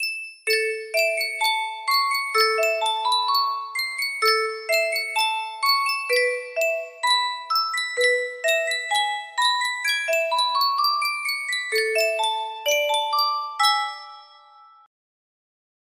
Sankyo Custom Tune Music Box - Stars and Stripes Forever music box melody
Full range 60